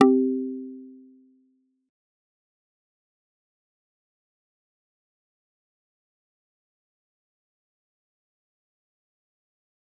G_Kalimba-C4-mf.wav